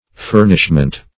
Search Result for " furnishment" : The Collaborative International Dictionary of English v.0.48: Furnishment \Fur"nish*ment\, n. The act of furnishing, or of supplying furniture; also, furniture.